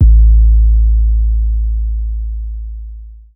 TS - 808 (10).wav